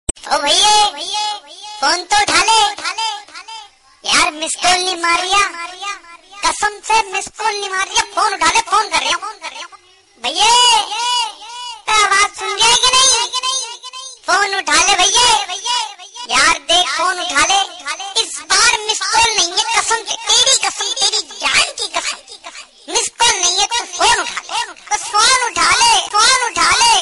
File Type : Funny ringtones